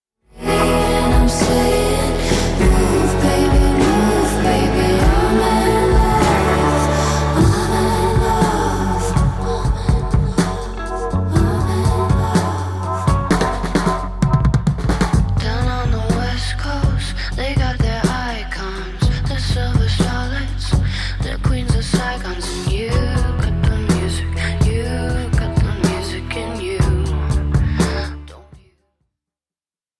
11,561 Post #7363 Better quality than that vevo rip: http